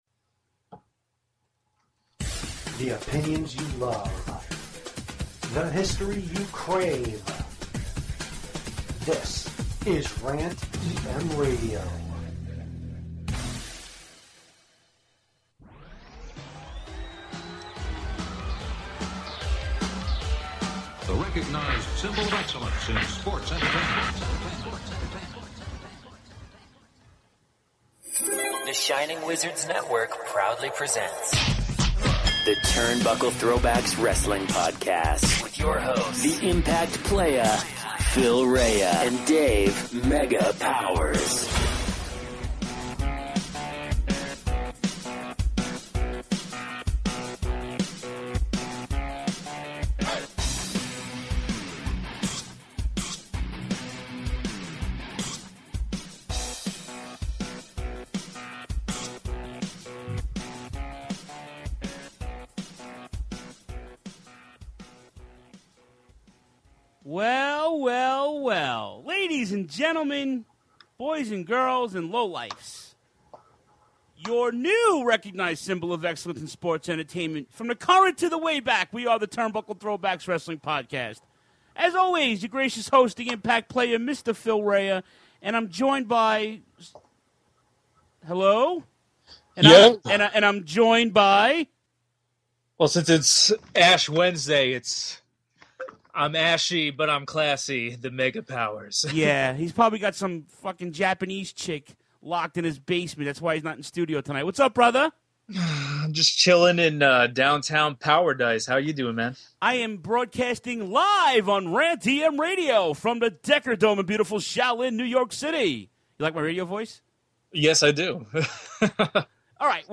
The boys go LIVE on RantEM radio!
We took some calls as well.